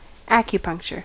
Ääntäminen
US : IPA : [a.kju.ˈpʌnk.t͡ʃə(r)]